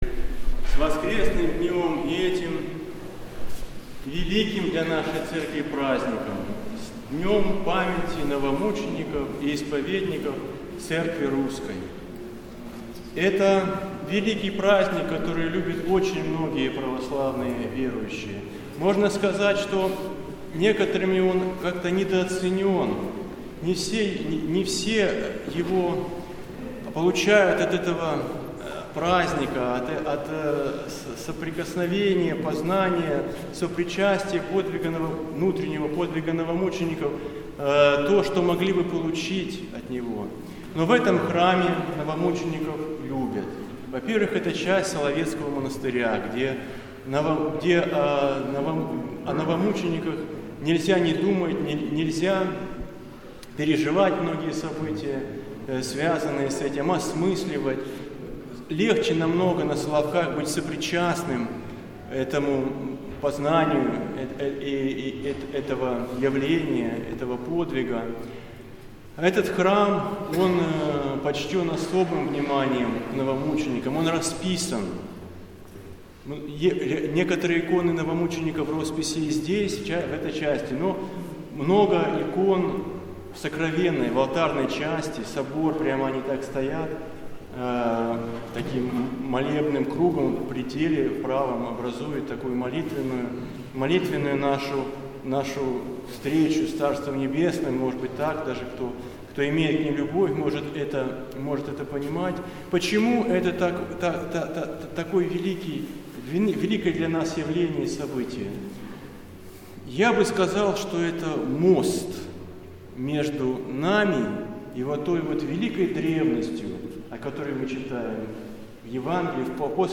Аудиозапись проповеди
Храм Великомученика и Победоносца Георгия в Ендове